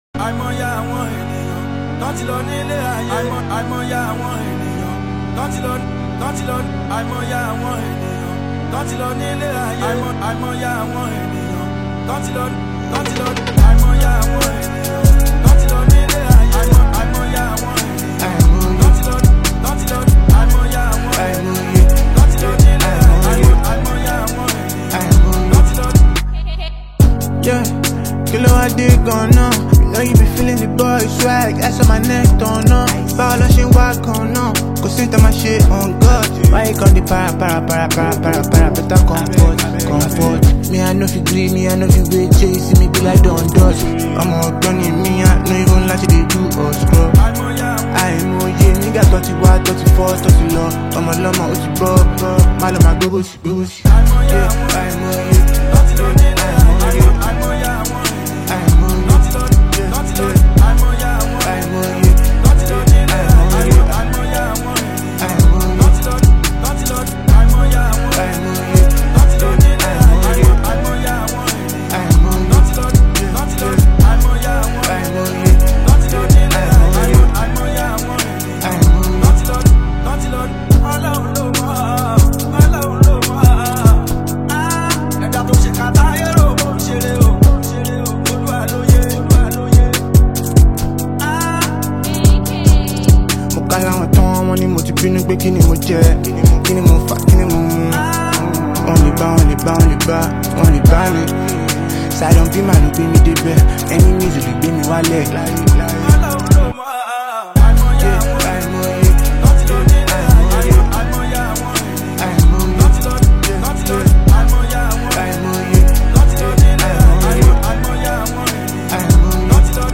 modern Afrobeats elements
old-school Fuji and contemporary Afrobeats